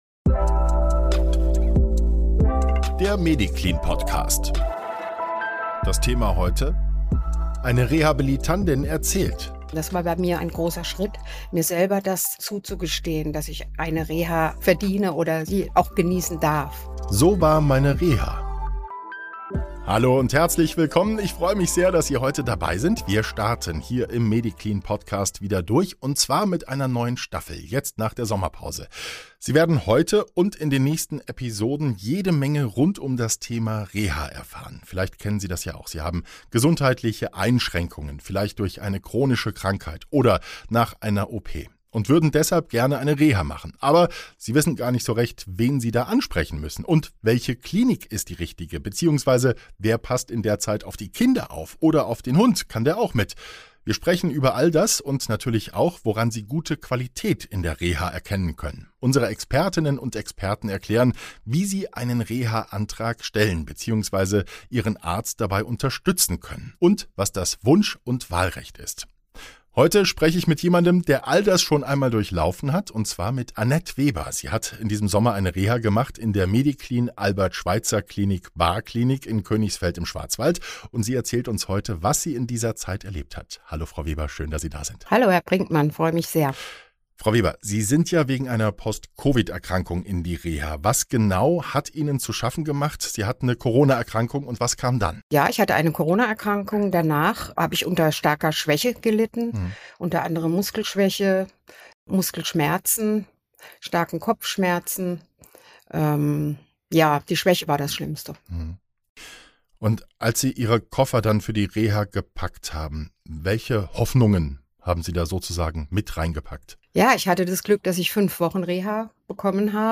Eine Rehabilitandin berichtet (Wdh.) ~ Der MEDICLIN Podcast